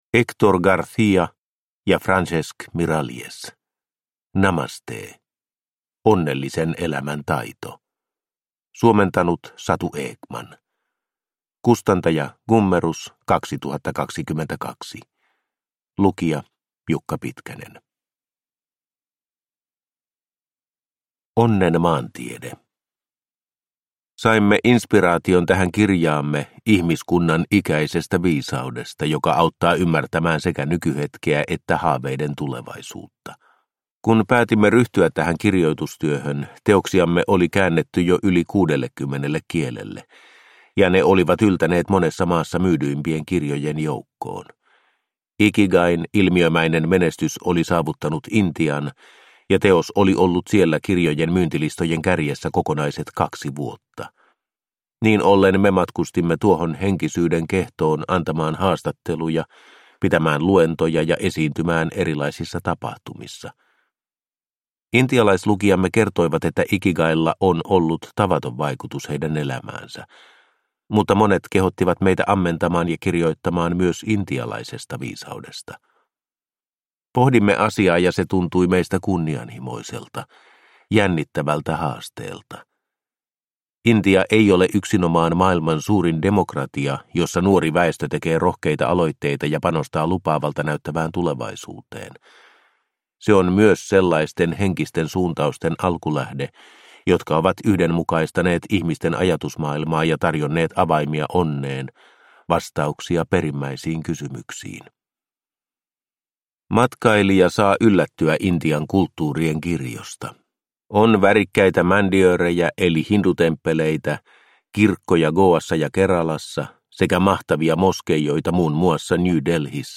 Namaste – Ljudbok – Laddas ner